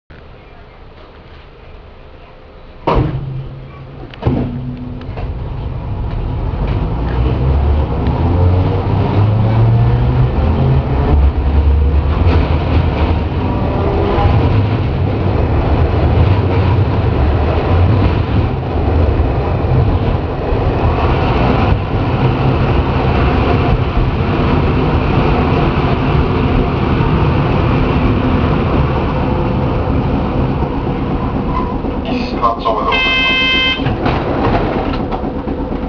〜車両の音〜
・250形走行音
【一条線】西４丁目→西８丁目…途中の信号から。（35秒：196KB）
見た目からして当然ではありますが、吊り掛け式です。煩いものの、一般の鉄道線用車両の吊り掛け式と比べると大分軽い音。車内放送をかき消すほどの物ではありません。